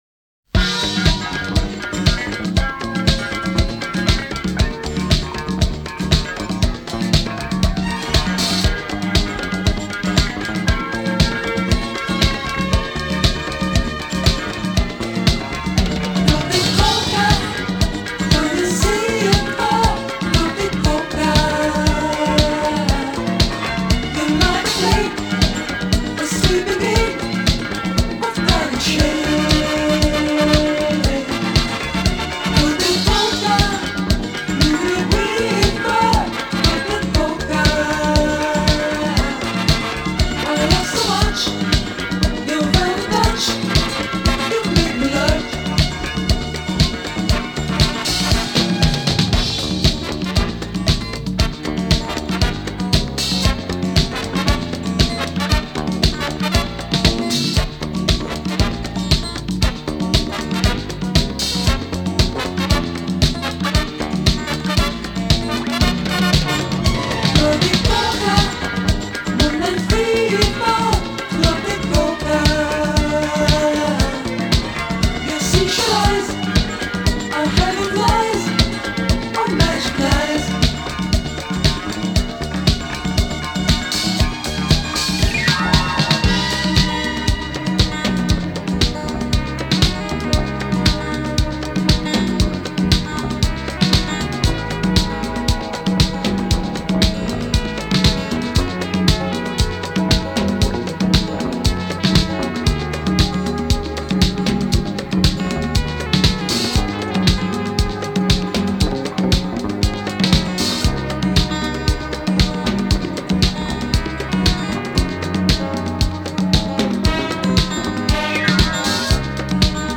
Filed under disco